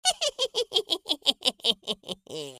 the-sound-of-laughing-witch